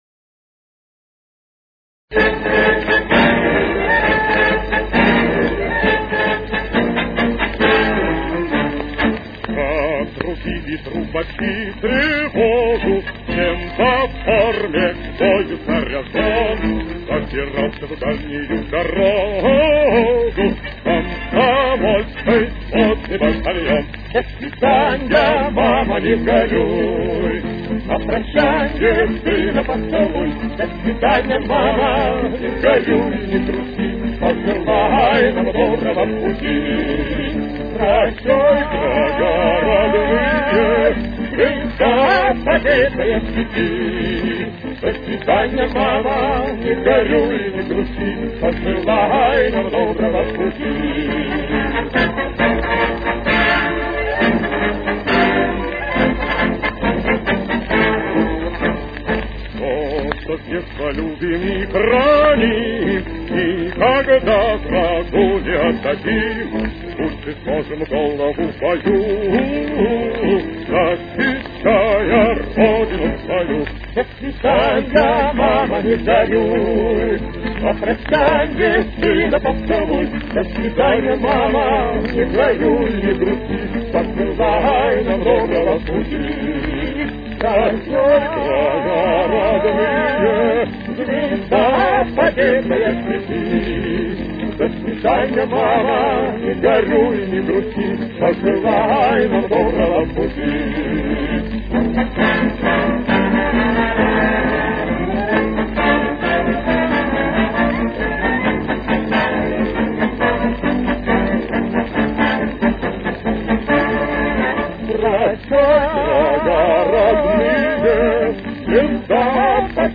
эстрадный певец 40-50-х годов 20 века.
с очень низким качеством (16 – 32 кБит/с)
Темп: 136.